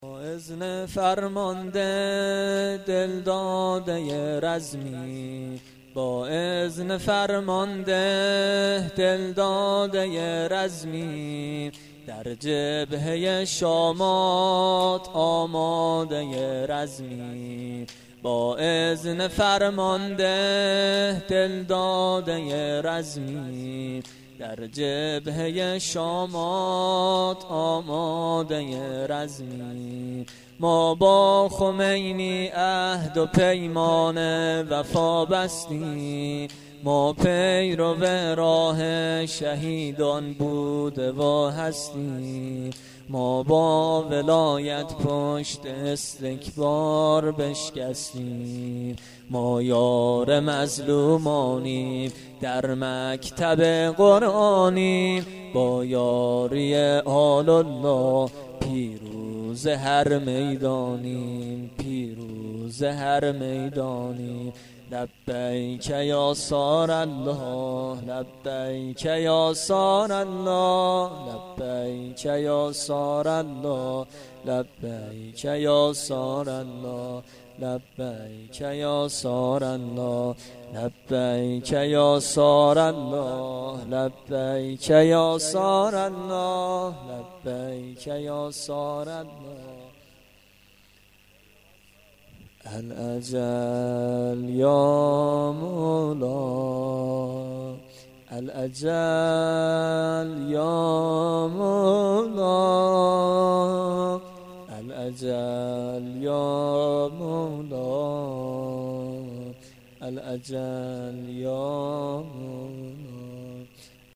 28.6.93شورحماسی1.mp3